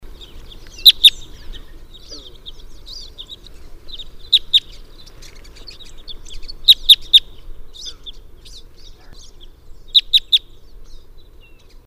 Red Crossbill (Loxia curvirostra)
Play MP3  Male (Type 2/pusilla) flight calls. Whitbourne, July 2005.